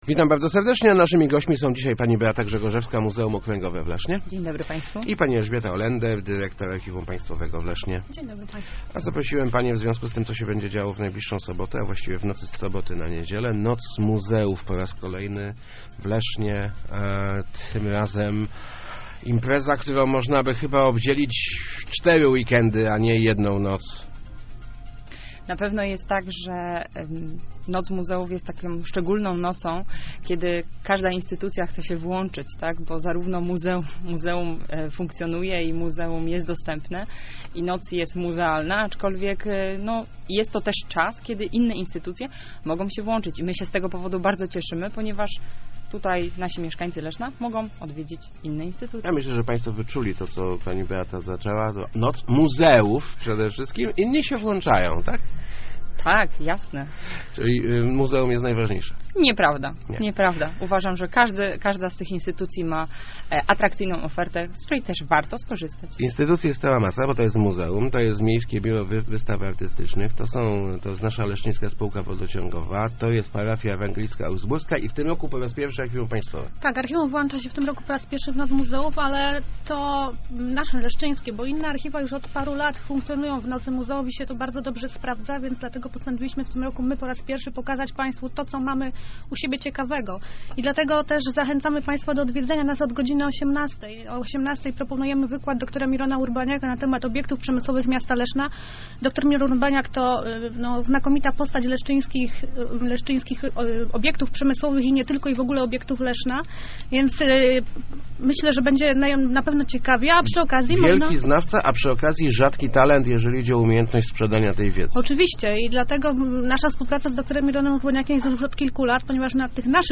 Start arrow Rozmowy Elki arrow Noc Muzeów w Lesznie